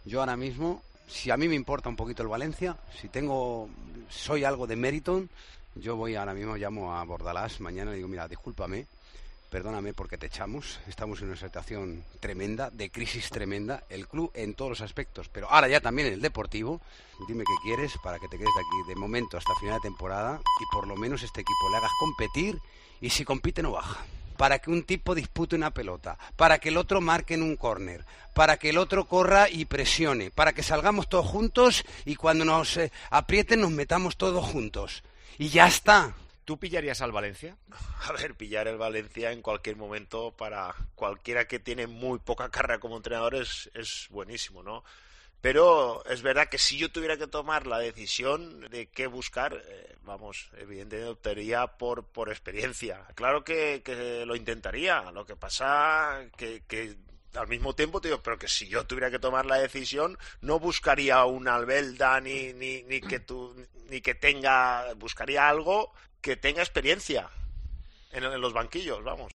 Los dos comentaristas del Valencia CF se muestran muy claros sobre sus preferencias de cara al banquillo che en Tiempo de Juego